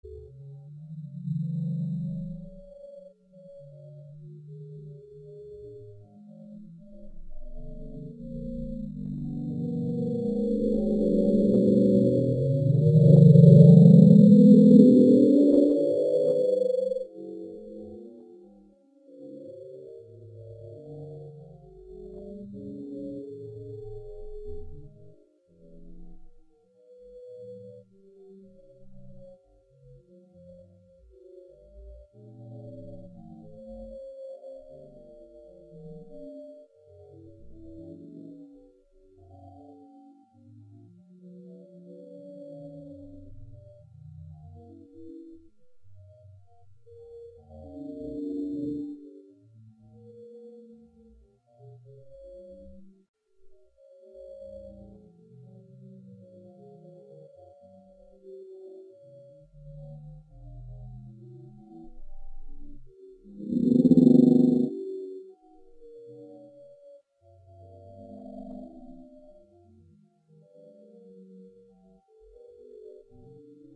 Звук Ктулху с глубины океана bloop